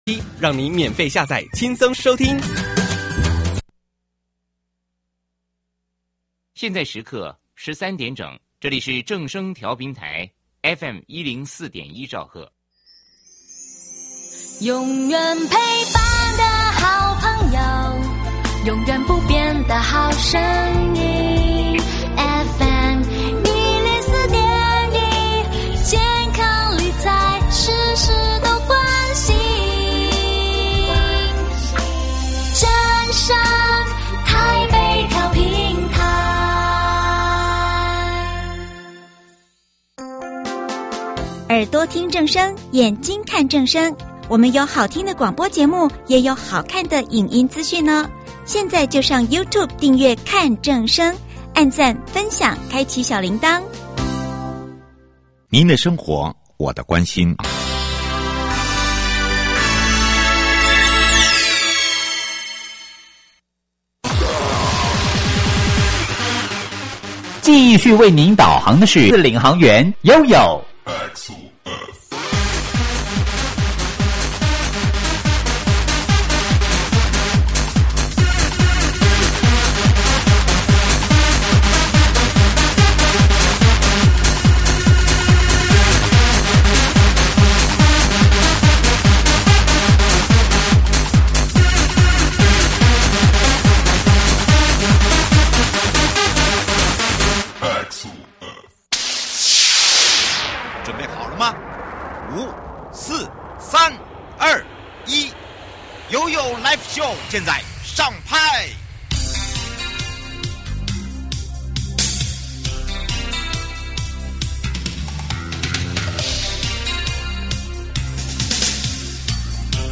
受訪者： 1.國土署都市基礎工程組